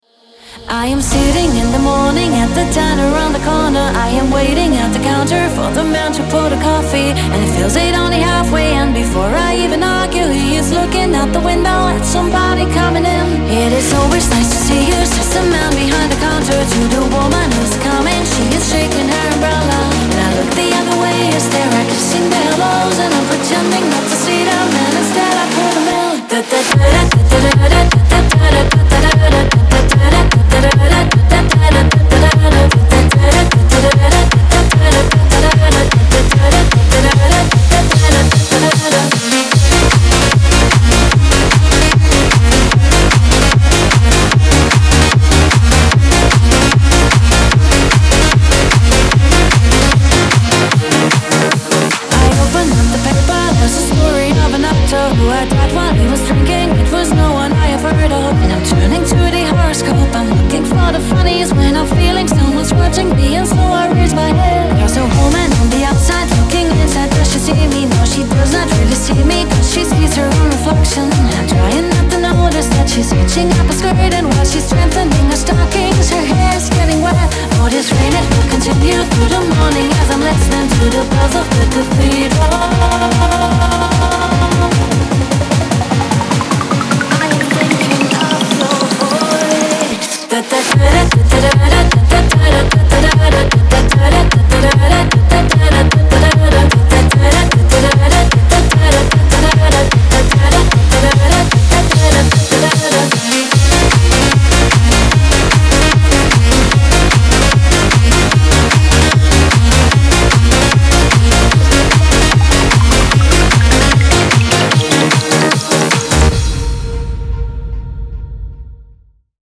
pop
дуэт